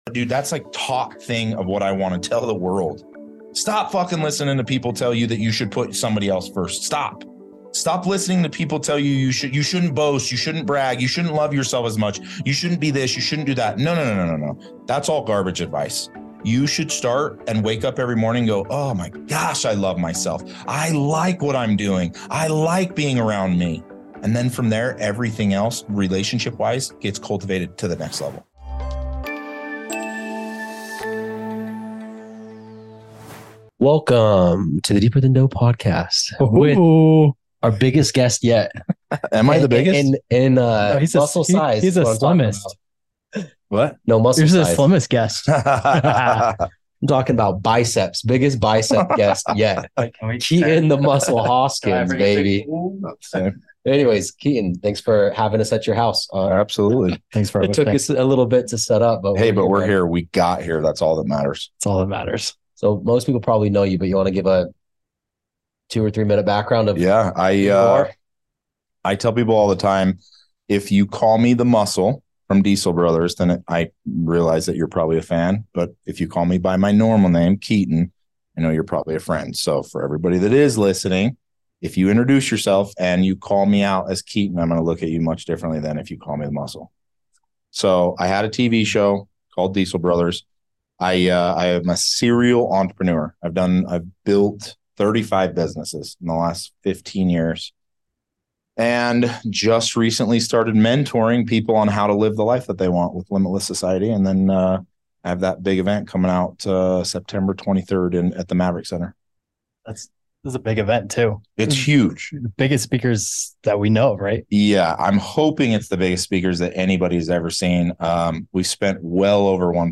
Join us for a dynamic and engaging discussion that will not only touch your heart but also ignite your passion for personal growth and making a positive impact in the world.